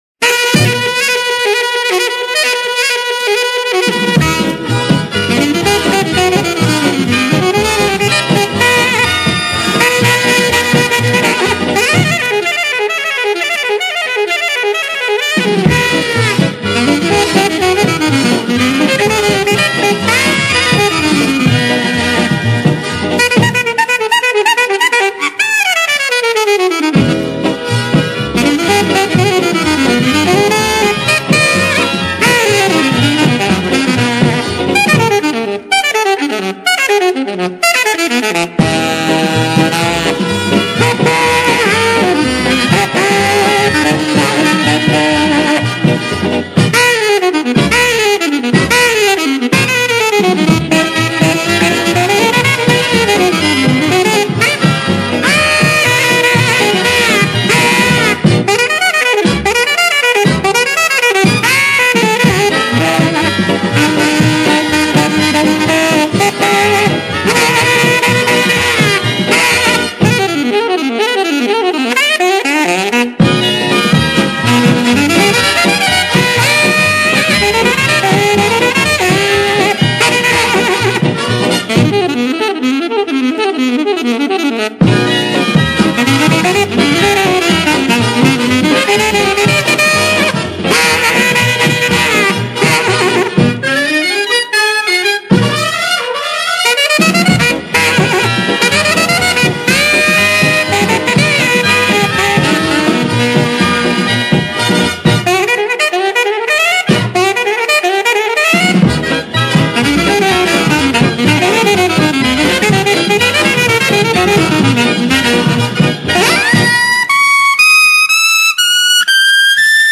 Big Band
• MAINSTREAM (JAZZ)
• Alto Sax
• Piano
• Guitar
• Bass
• Drums